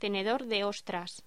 Locución: Tenedor de ostras